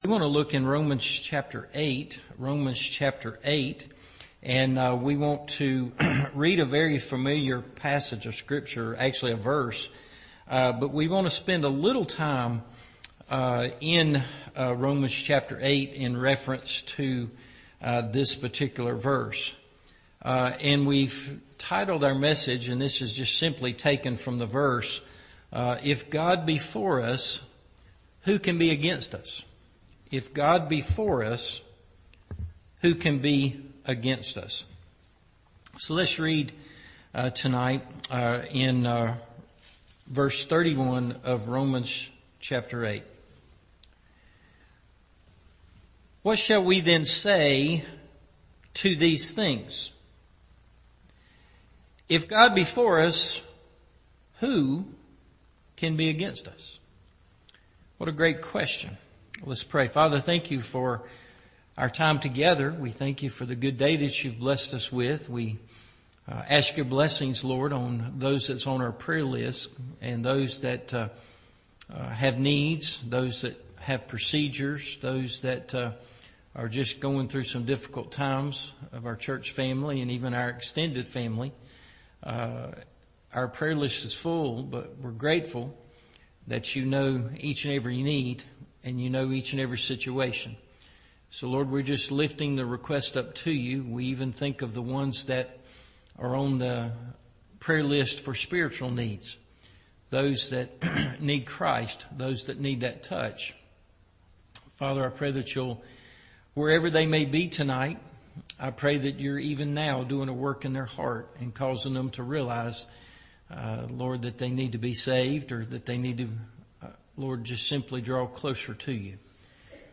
– Evening Service – Smith Grove Baptist Church